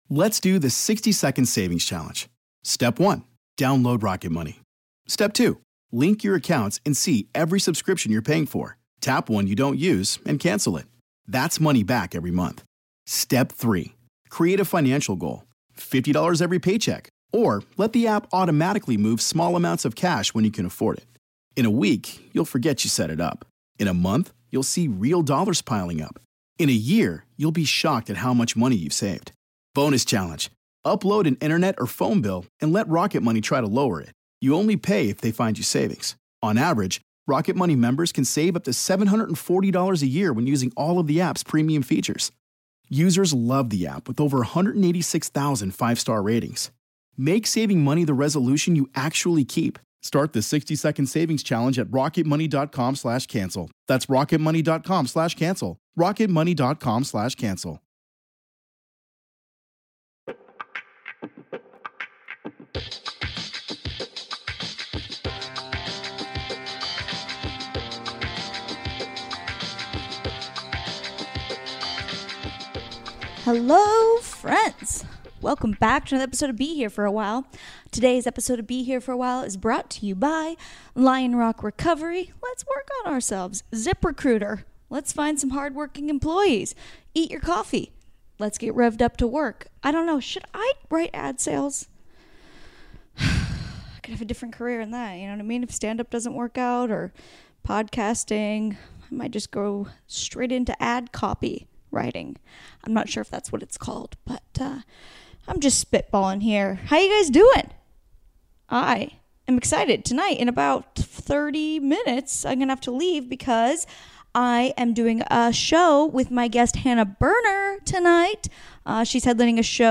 Education, Comedy, Comedy Interviews, Self-improvement